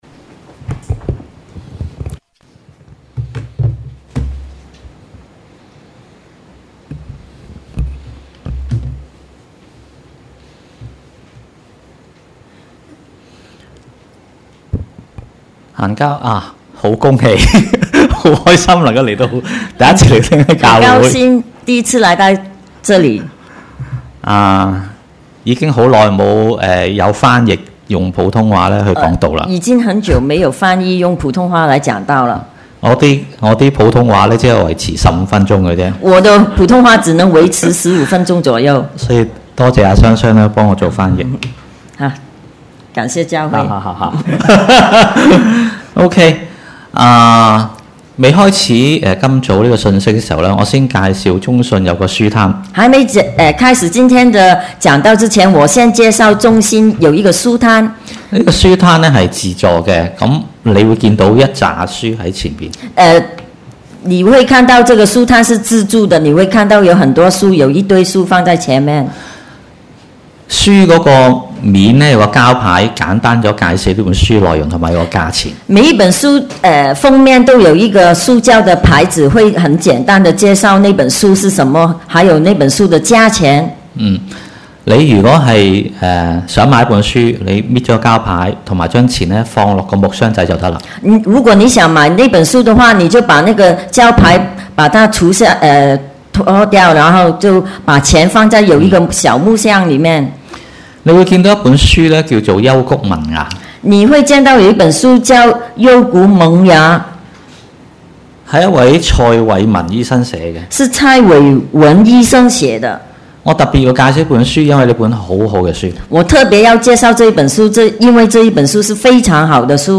Posted in 主日崇拜